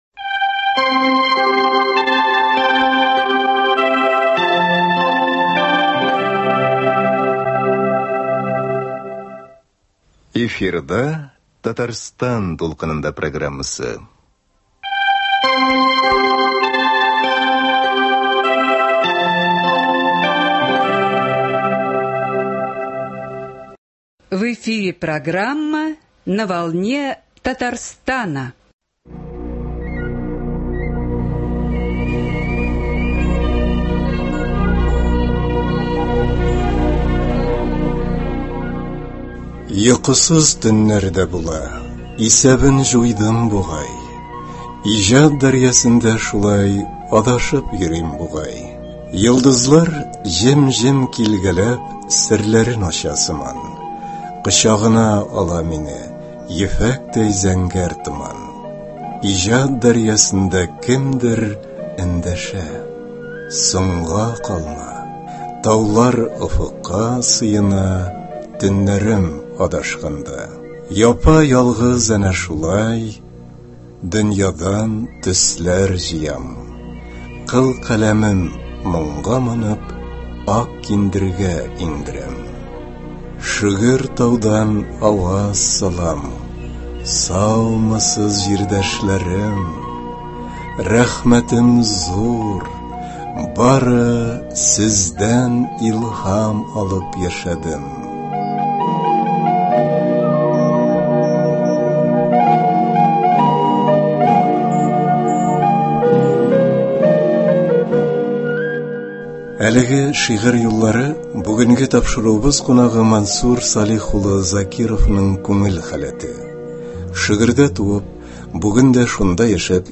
Тапшыруда әлеге чарадан язма тыңлый аласыз.